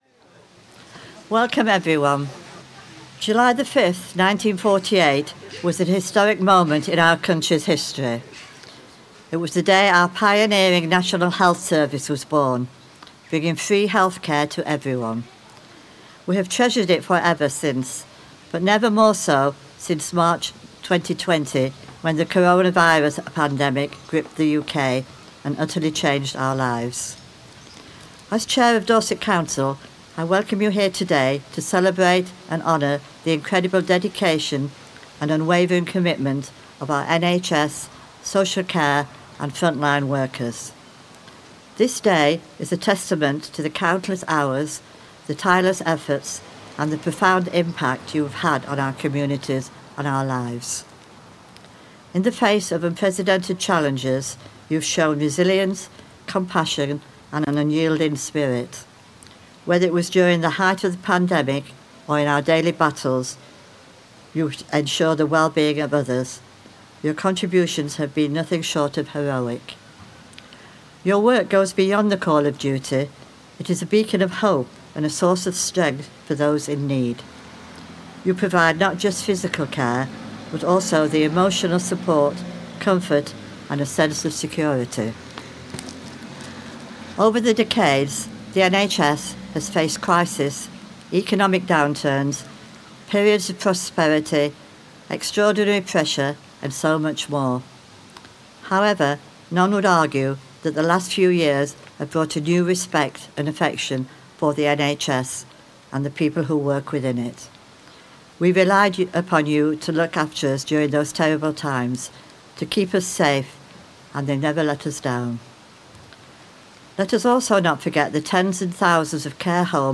In Dorchester on 5th July a flag-raising ceremony celebrating the work of the NHS, Social Care and Frontline Workers was held at the Memorial Area outside County Hall.
Here is our recording of the ceremony which begins with an introduction by the Chair of Dorset Council, Cllr Stella Jones…